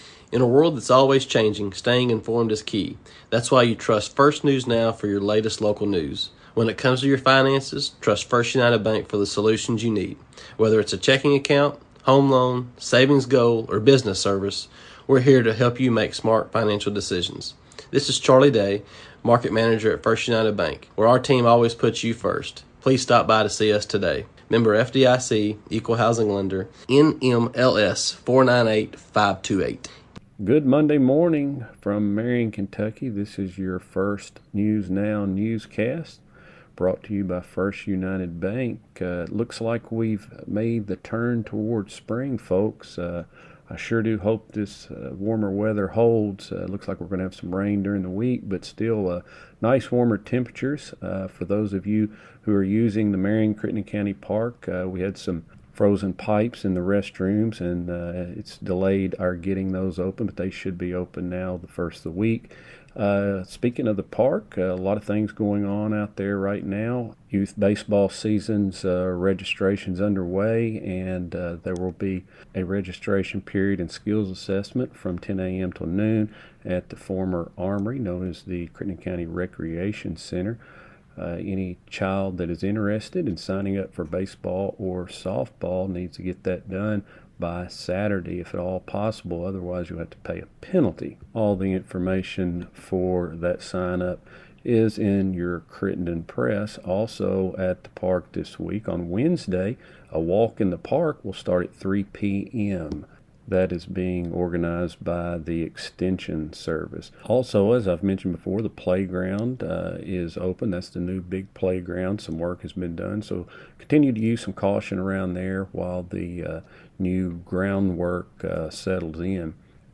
MONDAY FIRST NEWS NOW LISTEN NOW ============== First News Now NewsCast powered by First United Bank By Crittenden Press Online at March 03, 2025 Email This BlogThis!